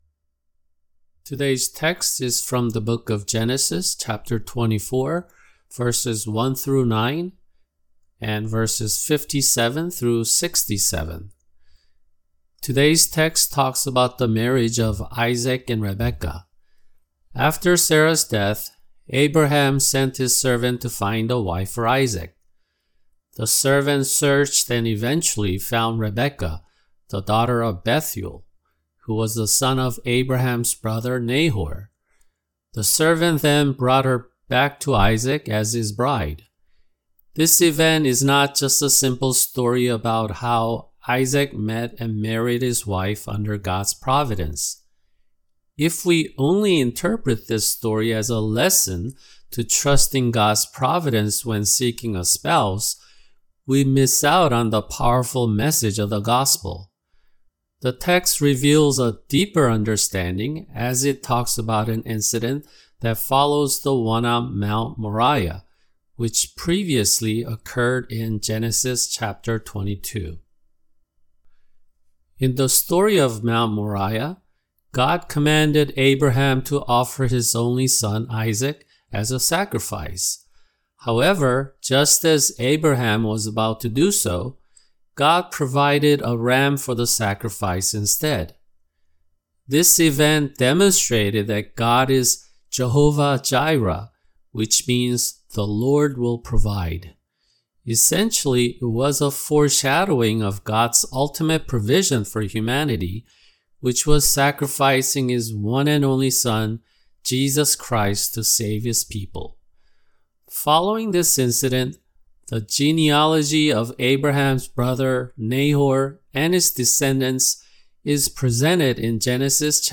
[주일 설교] 계시록 4:1-11(1)